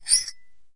玻璃 陶瓷 " 陶瓷冰激凌碗用金属勺子刮削 01
描述：用金属勺刮一个陶瓷冰淇淋碗。 用Tascam DR40录制。
Tag: 刮下 金属勺 勺子 金属 陶瓷